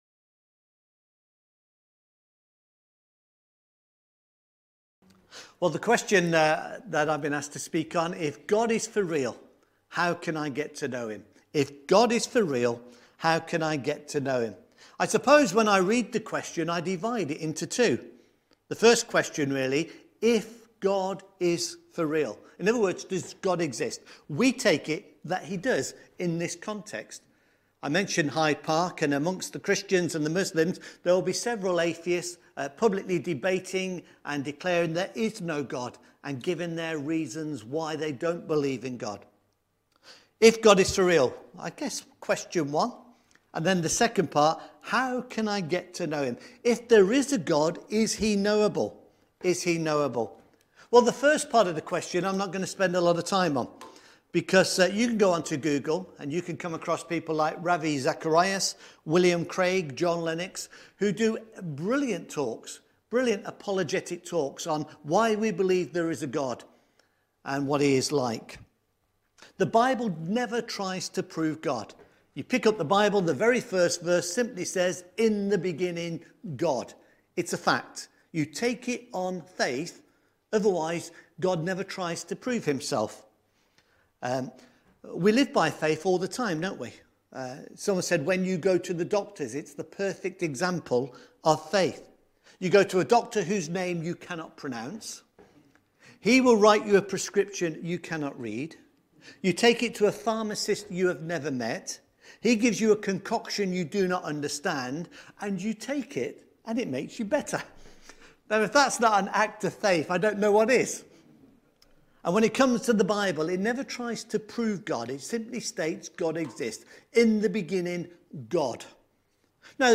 Living Water For a Thirsty Soul– Zoom meeting with Cannon Court Evangelical Church, Fetcham, UK. Bible Readings: Isaiah chapter 12 verses 1-6 & John chapter 7 verse 37 to 39.